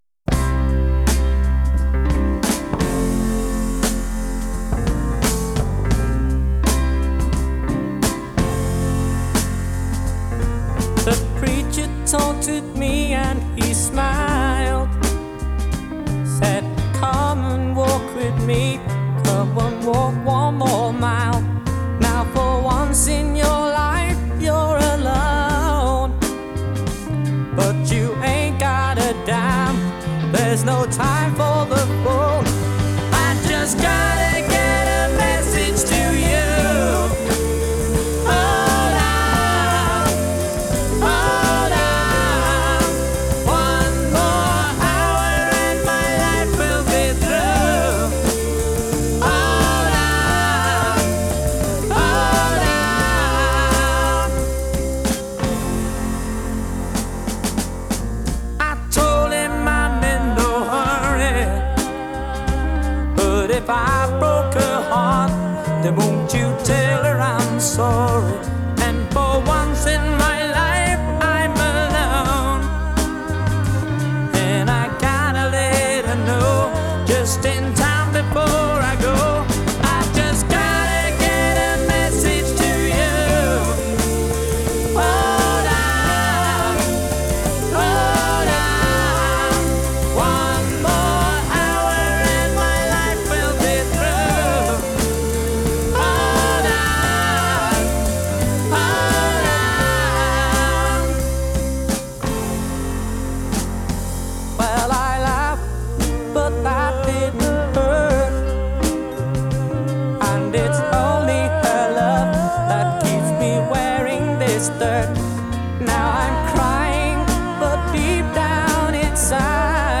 Genre: Pop, Pop Rock, Disco